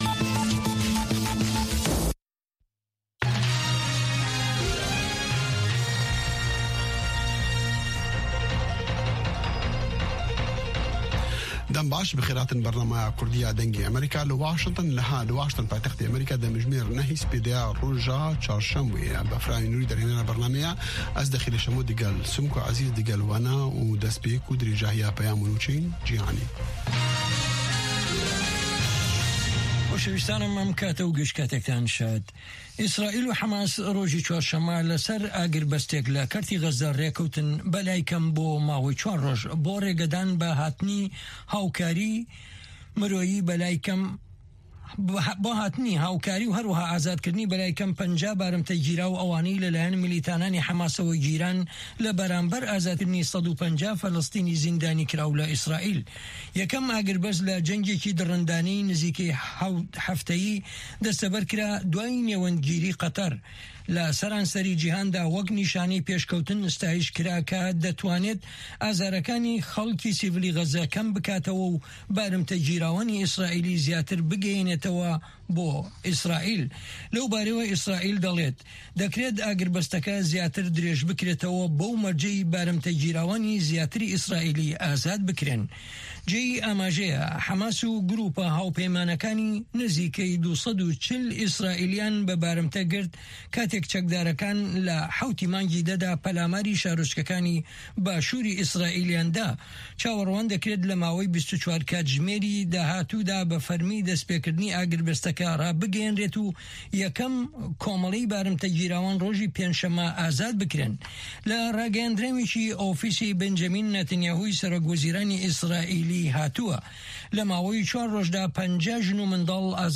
Nûçeyên Cîhanê 1
Nûçeyên Cîhanê ji Dengê Amerîka